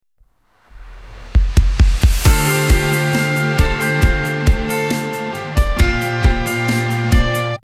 （无歌词，纯音乐）